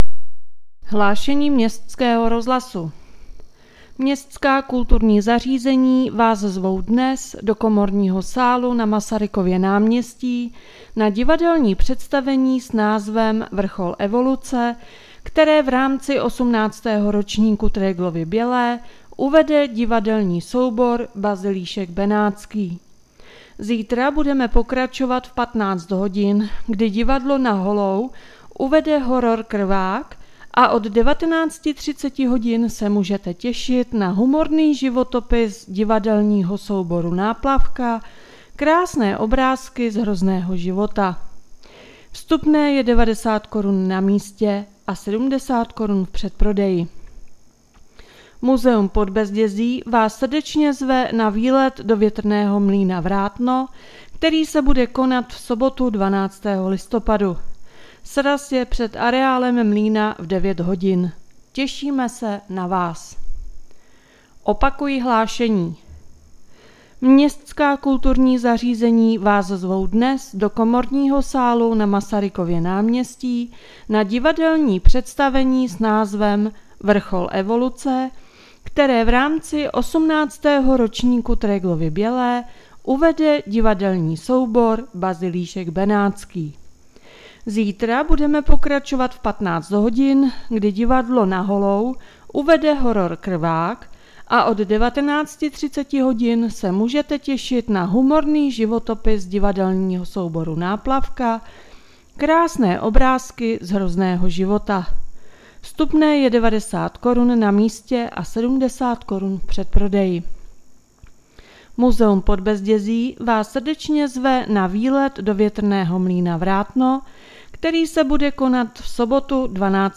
Hlášení městského rozhlasu 11.11.2022